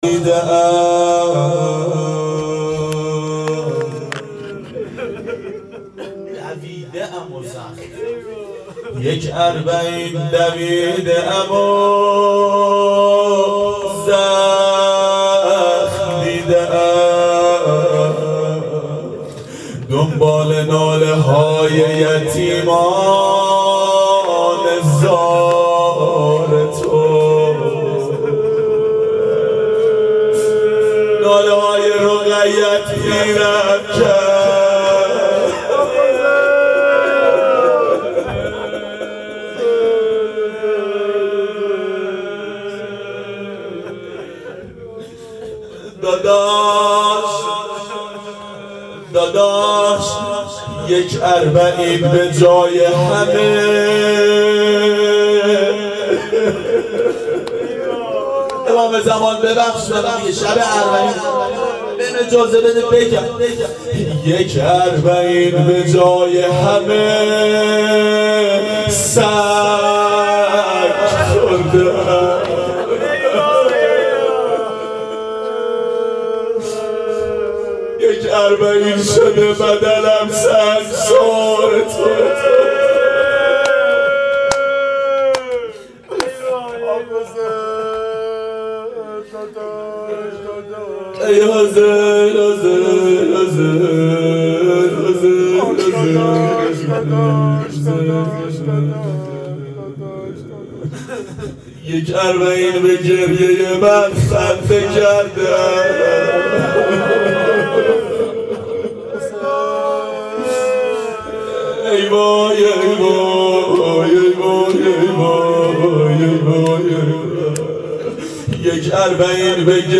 روضه شب اربعین
شب اربعین 92 هیأت عاشقان اباالفضل علیه السلام منارجنبان
01-روضه-شب-اربعین.mp3